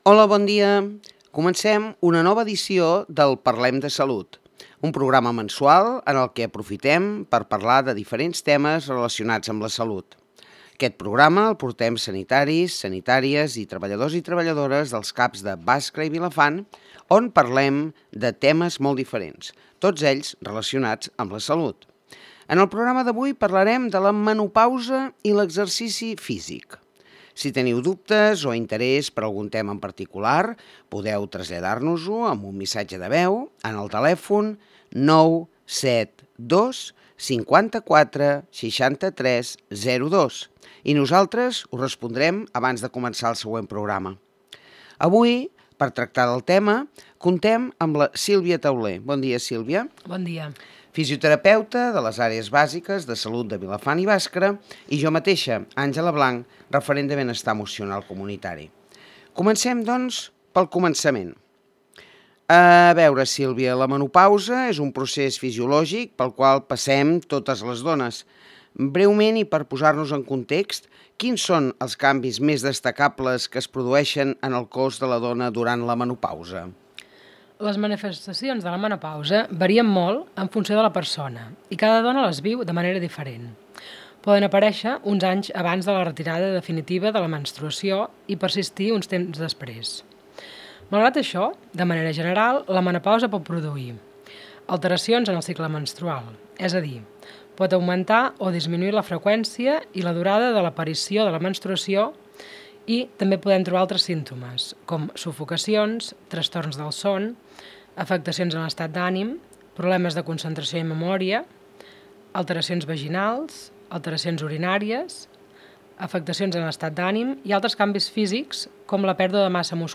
Les especialistes del CAP de Vilafant ens parlen sobre la menopausa i l’exercici físic, dins l’espai que dediquem a parlar sobre salut.